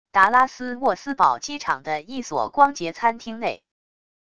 达拉斯沃斯堡机场的一所光洁餐厅内wav音频